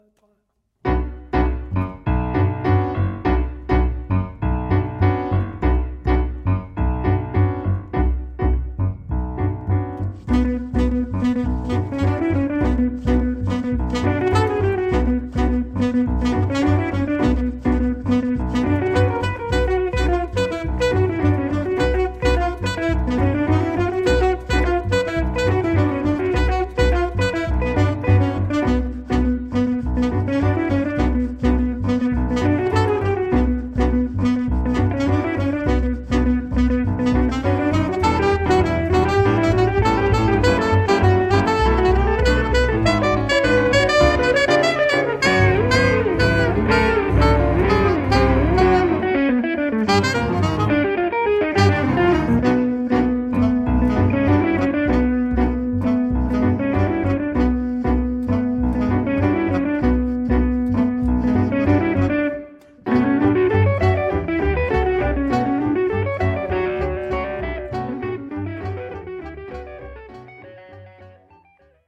in einer eher Jazzcombo-typischen Besetzung